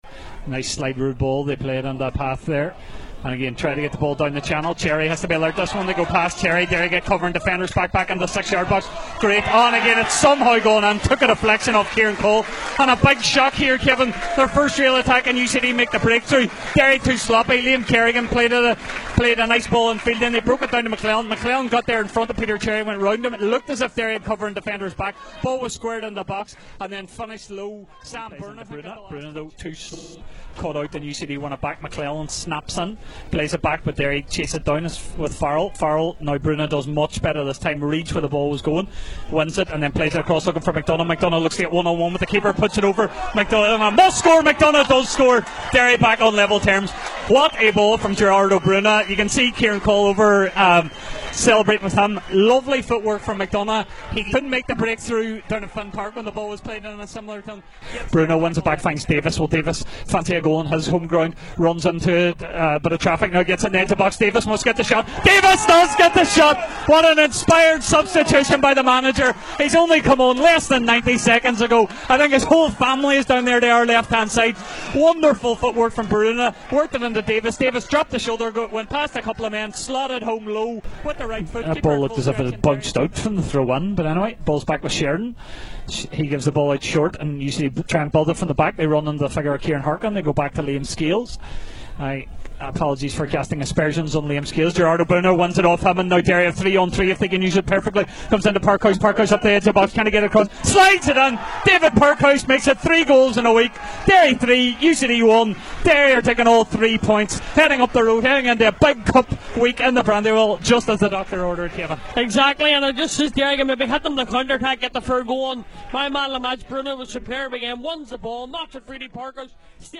Derry City go into 3rd place with win over UCD. We have goal highlights plus post match reaction